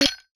UIClick_Metal Hits Muffled 04.wav